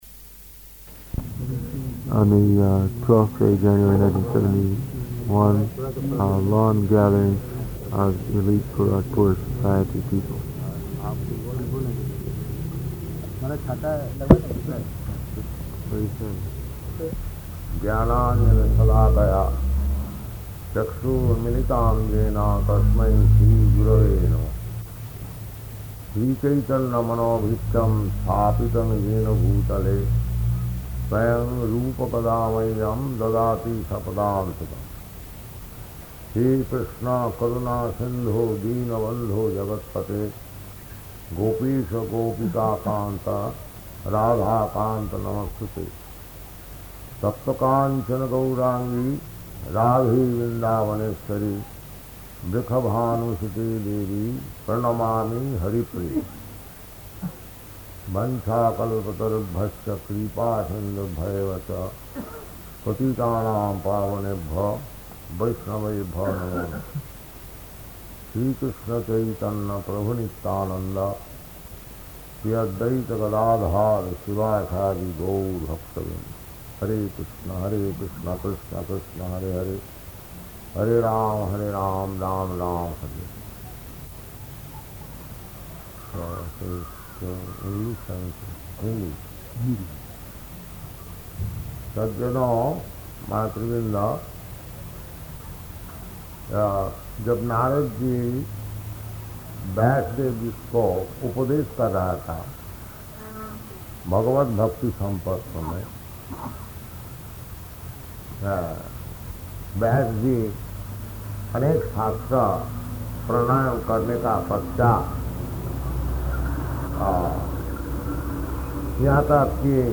Lecture in Hindi
Type: Lectures and Addresses
a lawn gathering